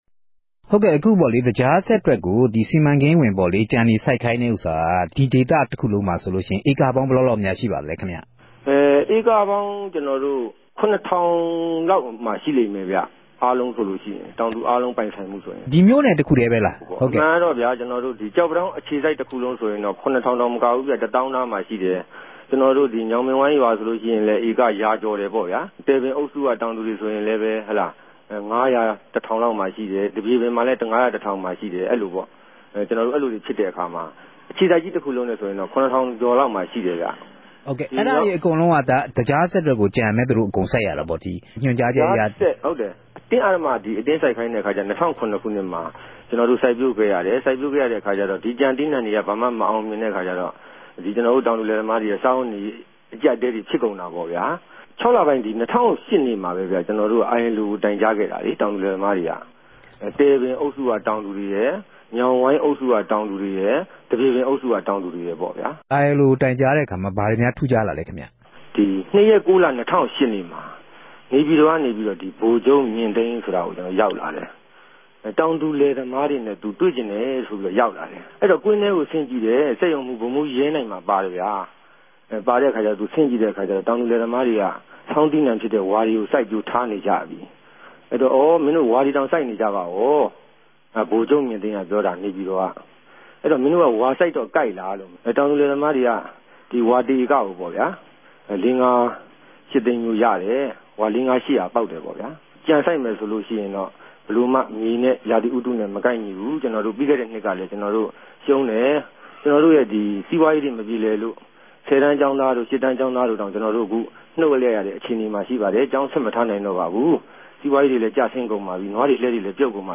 တောင်သူတဦးိံြင့် ဆက်သြယ်မေးူမန်းခဵက်။